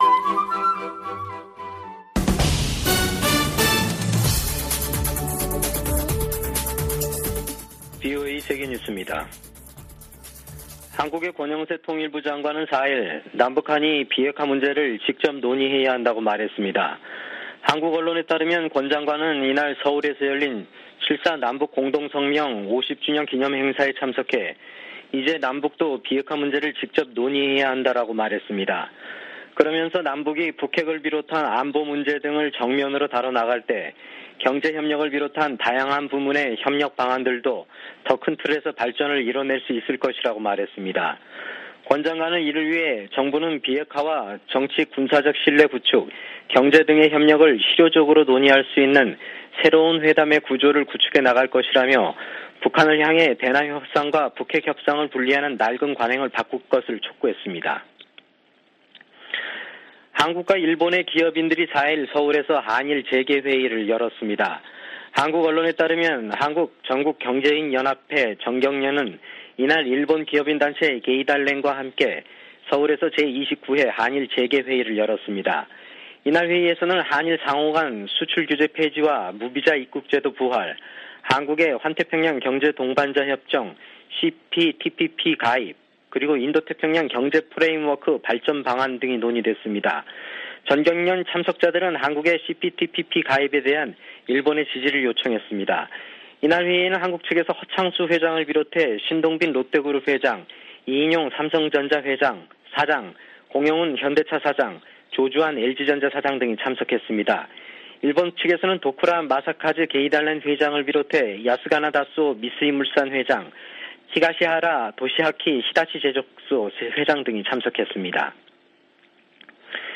VOA 한국어 아침 뉴스 프로그램 '워싱턴 뉴스 광장' 2022년 7월 5일 방송입니다. 미국은 북한의 핵·미사일 도발에 대응하고 한반도 비핵화를 위해 동맹과 협력할 것이라고 미군 당국이 밝혔습니다. 위협을 가하는 북한에 일방적으로 대화와 협력을 요청해서는 변화시킬 수 없다고 전 국무부 동아시아태평양 담당 차관보가 진단했습니다. 미국 전문가들은 중국에 대한 한국의 전략적 모호성은 이익보다 대가가 클 것이라고 말했습니다.